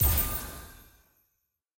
sfx-exalted-hub-button-currency-click.ogg